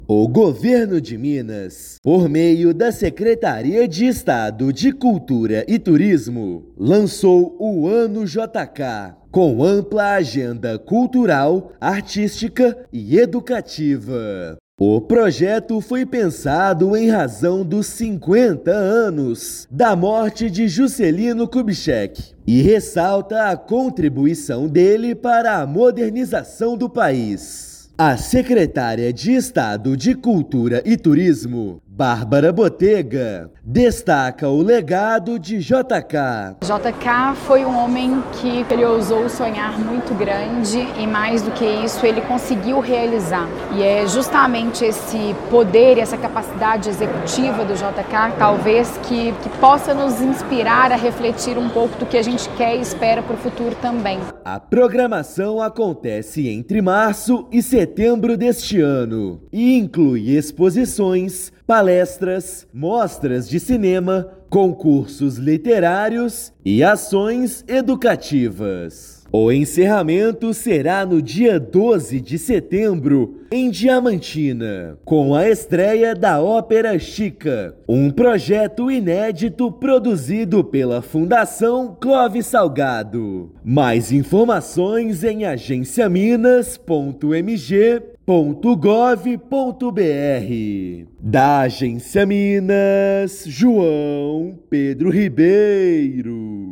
[RÁDIO] Governo de Minas lança o Ano JK com programação cultural integrada em Minas Gerais
Exposições, debates, ações educativas e espetáculos em BH e Diamantina compõem o projeto que celebra o legado nos 50 anos de morte de Juscelino Kubitschek. Ouça matéria de rádio.